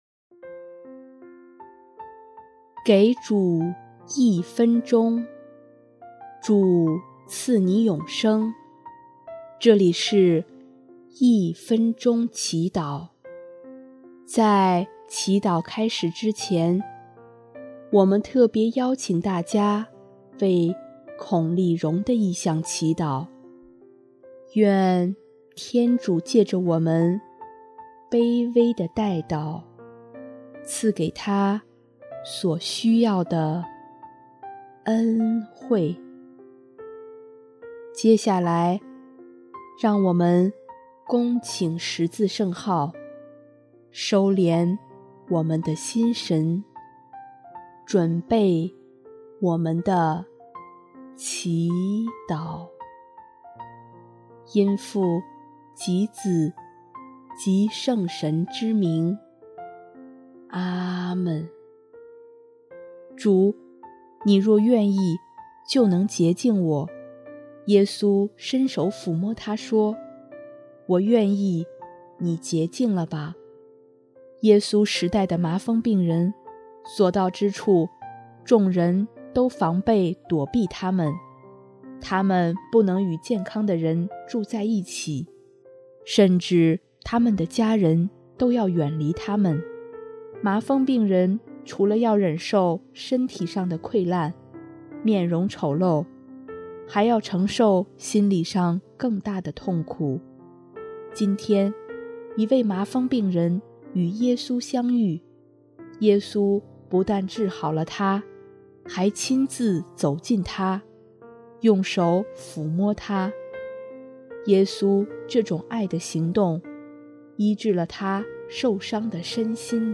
音乐：主日赞歌《求你洁净我》